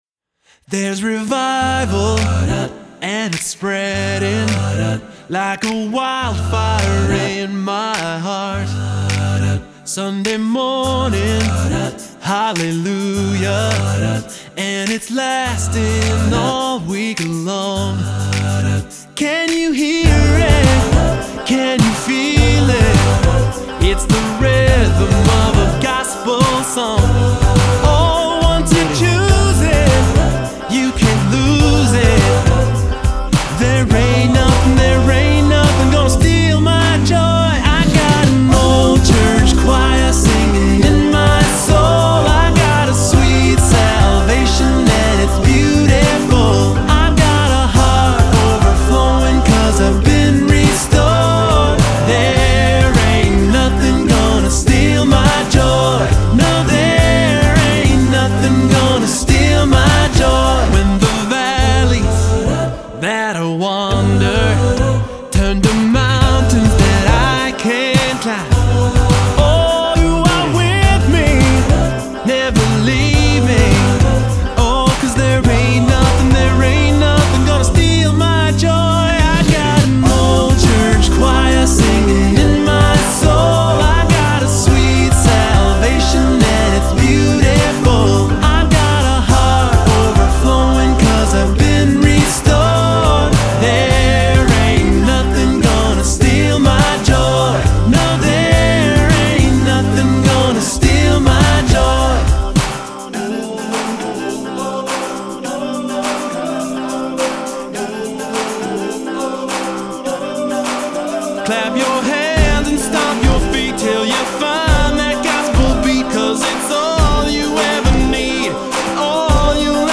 Genre: Acapella